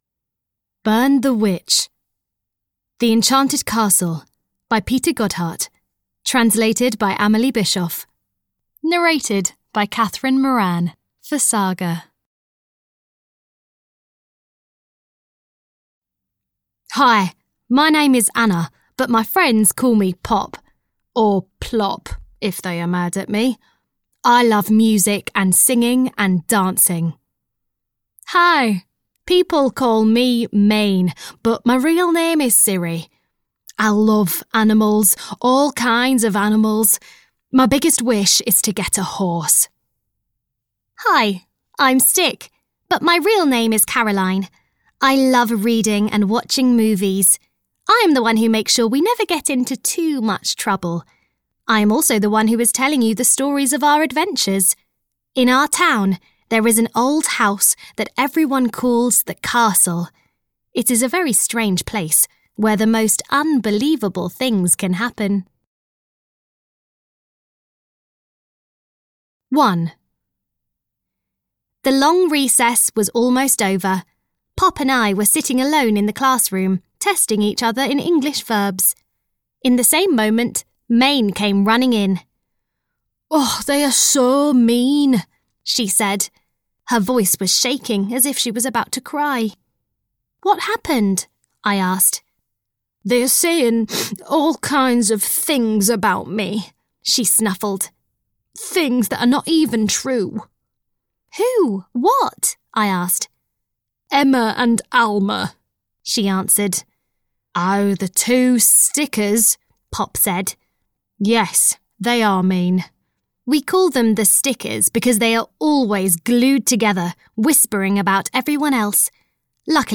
Audio knihaThe Enchanted Castle 8 - Burn the Witch! (EN)
Ukázka z knihy